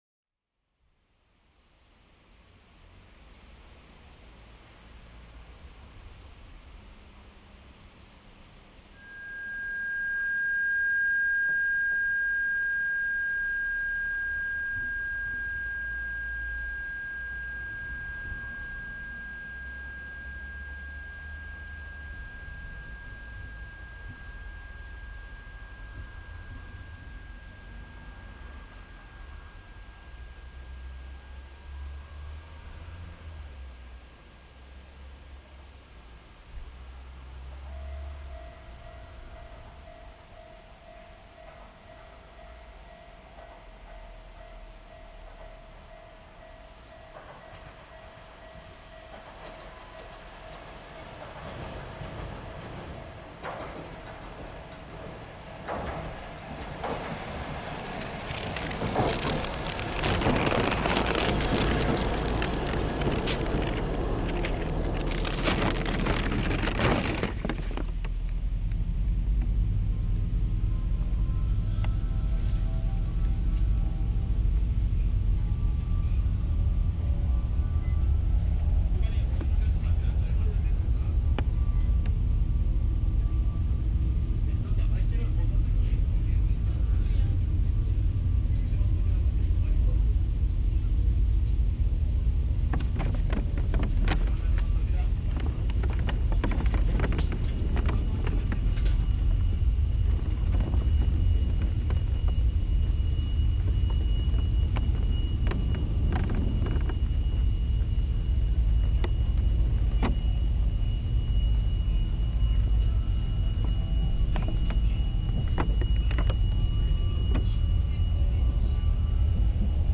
I’ve slowly been assembling two difficult compositions that replicate events during the non-linear recovery of my ears.
The dynamic range of my ears was reduced, as if passing through a limiting amplifiers.
And they seemed to be independently subject to automated low pass filters.
At times ambient sound was absent and at others it was distorted, inharmonic; similar to driven tape or valves.
This saturated base state was accompanied by dislocated events – an evolving and intricate tinnitus, discrete in each ear.